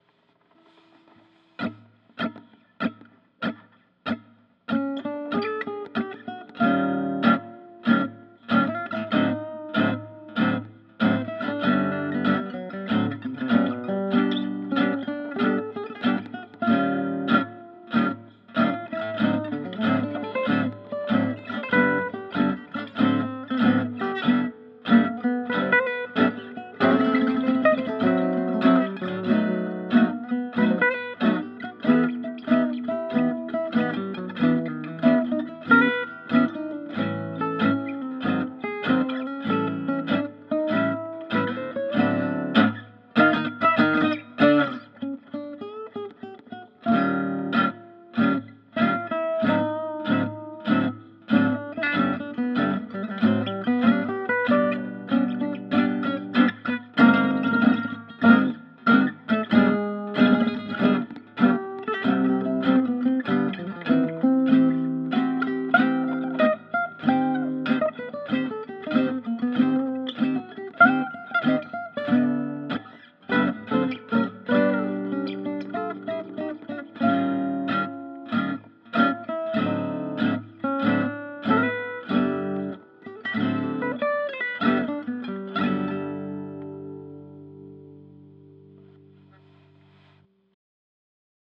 With Shure510c vintage mic IR
Actually a perfectly serviceable sound.